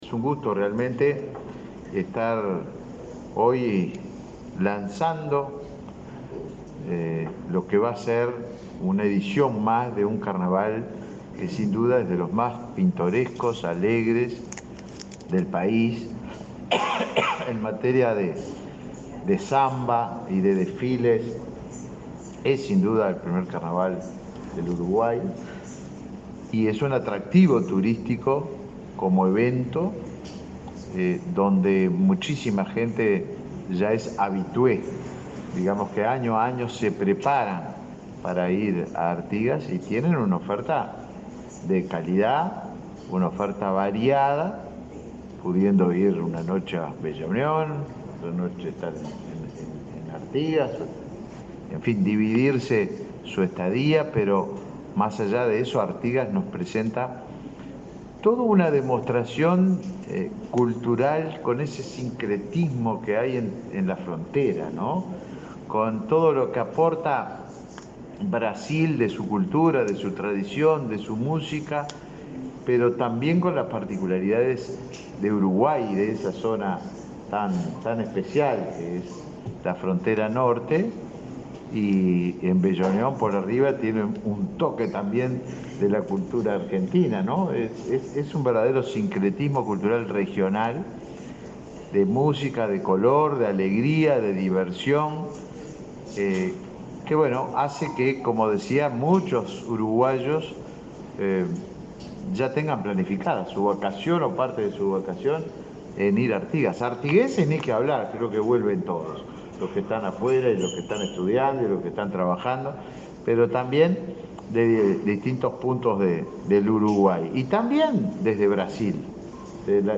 Palabras del ministro de Turismo, Tabaré Viera
El ministro de Turismo, Tabaré Viera, participó, en la sede de esa cartera, en el lanzamiento del Carnaval de Artigas.